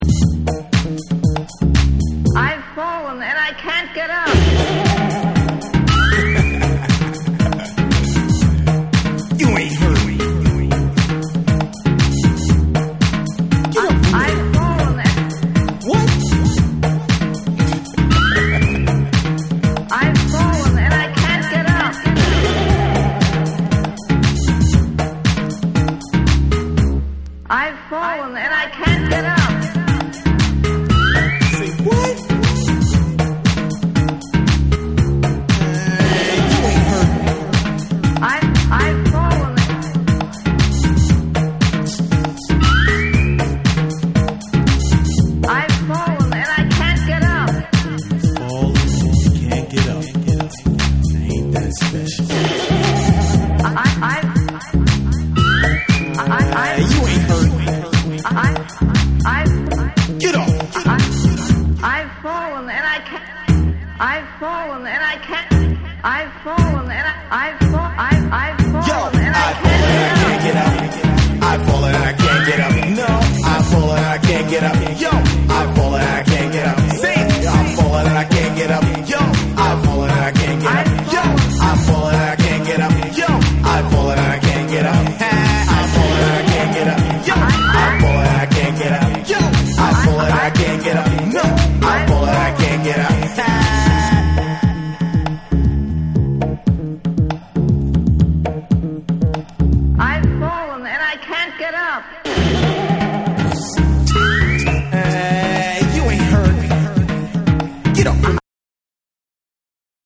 ディスコティークなトラックに、ヴォイスサンプルやファニーな効果音を配しグルーヴィーに展開する1。
TECHNO & HOUSE / BACK TO BASIC